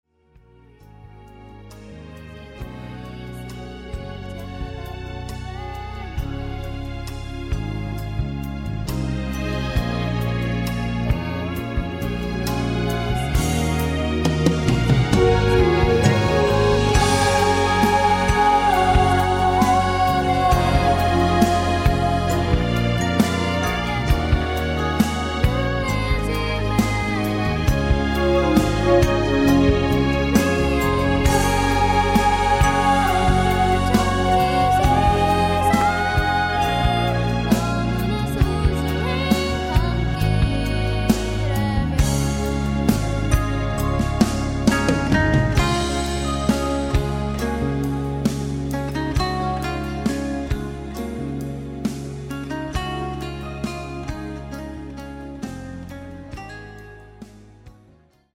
음정 원키 4:18
장르 가요 구분 Voice MR